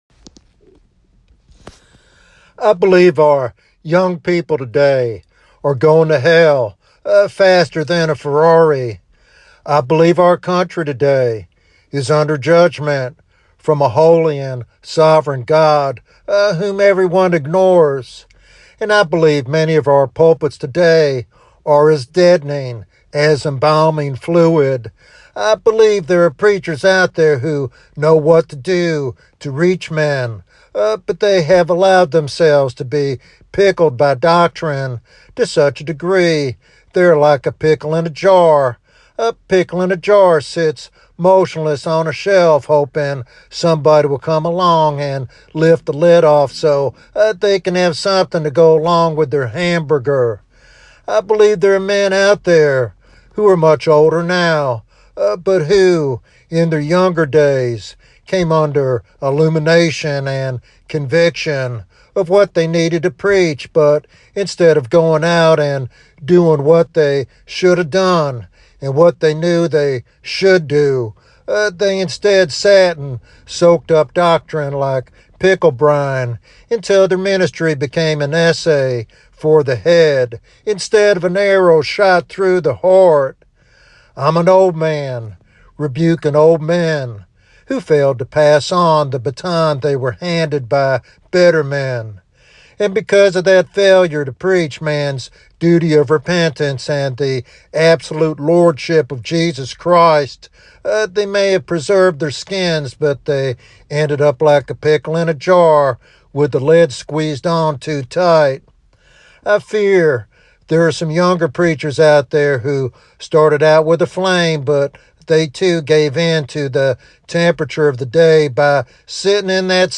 This sermon is a passionate plea for renewed spiritual fervor and gospel-centered ministry.